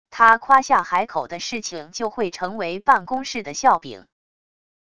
他夸下海口的事情就会成为办公室的笑柄wav音频生成系统WAV Audio Player